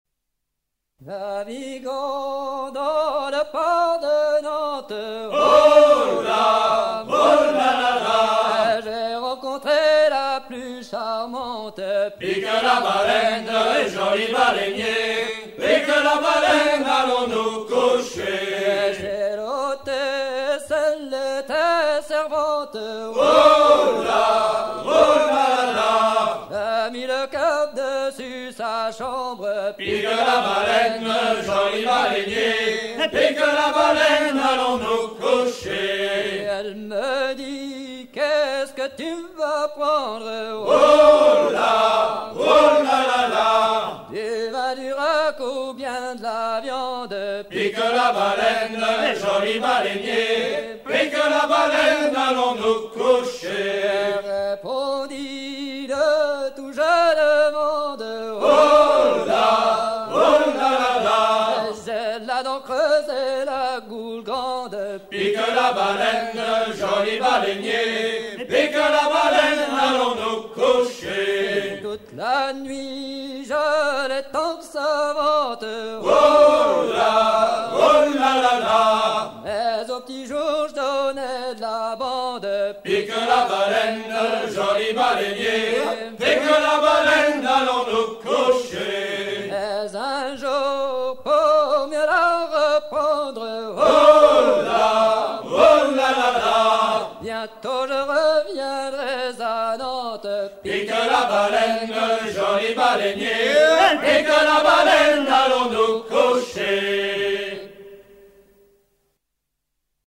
gestuel : à virer au cabestan
Genre laisse
Catégorie Pièce musicale éditée